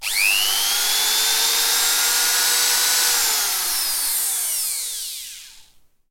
missileAssembly.ogg